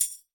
Tag: 多次采样 塔姆伯林 样品 铃鼓 编辑